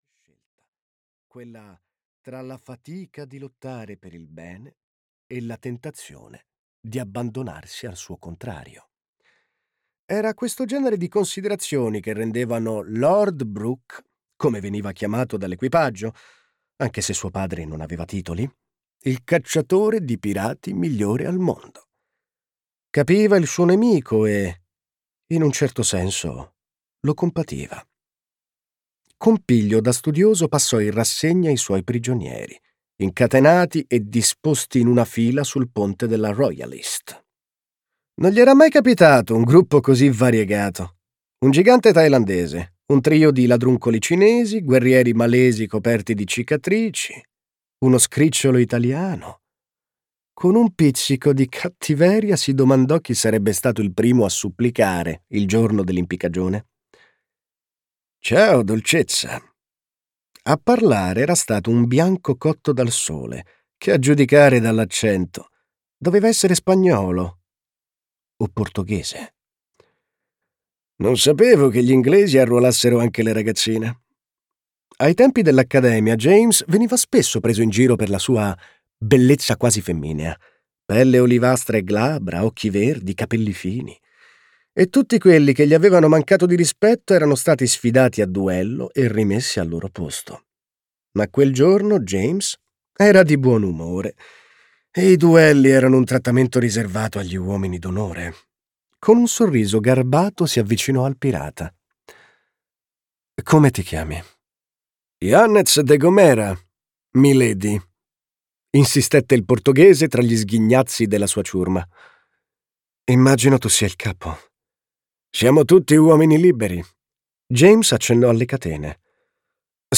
"Sandokan" di Alessandro Sermoneta - Audiolibro digitale - AUDIOLIBRI LIQUIDI - Il Libraio